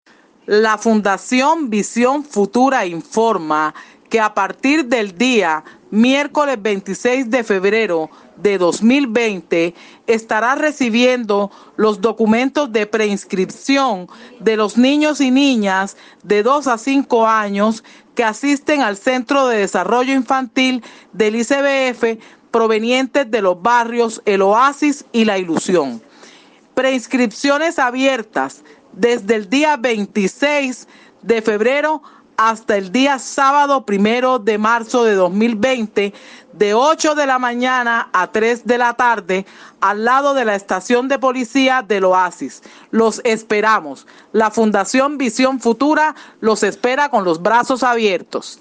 El Sábado de Carnaval y el Miércoles de Ceniza los habitantes de El Oasis, en Soledad, fueron sorprendidos por un perifoneo en el que citaban a una reunión a los padres de los niños que son atendidos por el Bienestar Familiar en el Centro de Desarrollo Integral de su barrio.
Feb28_perifoneo.mp3